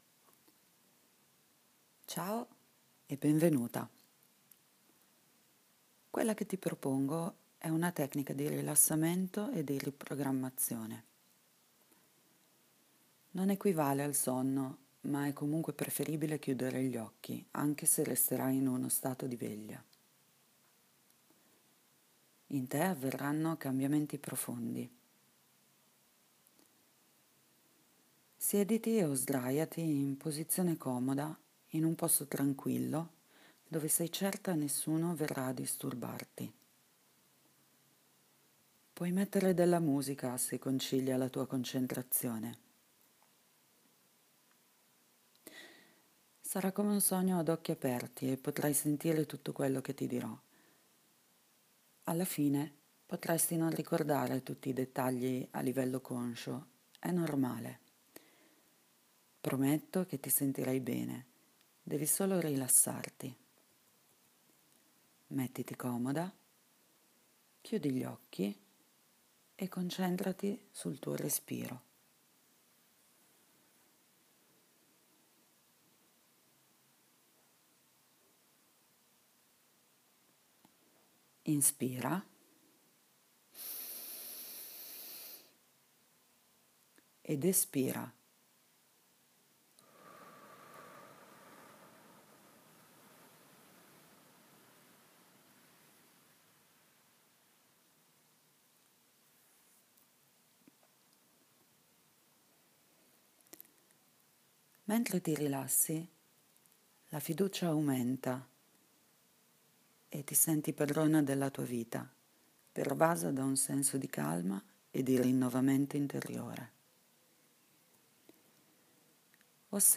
Meditazione.m4a